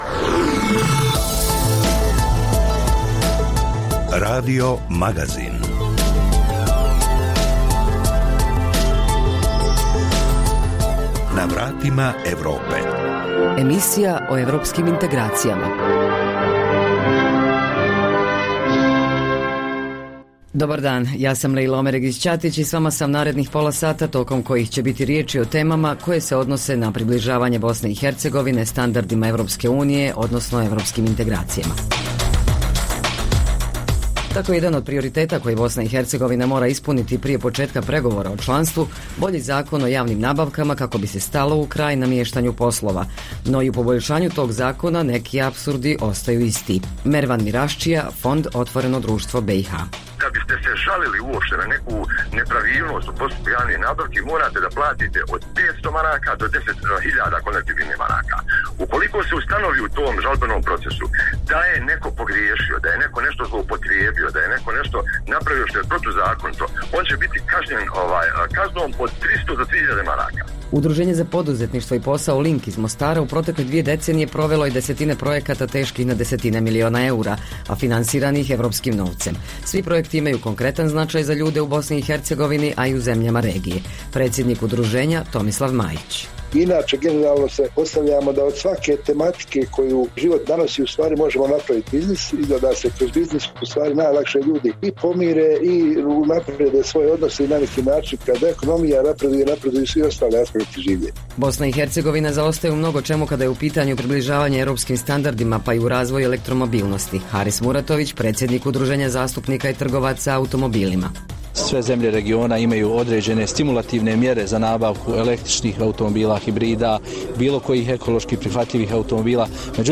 Emisija o putu BiH ka Evropskoj uniji i NATO sadrži vijesti, analize, reportaže i druge sadržaje o procesu integracije.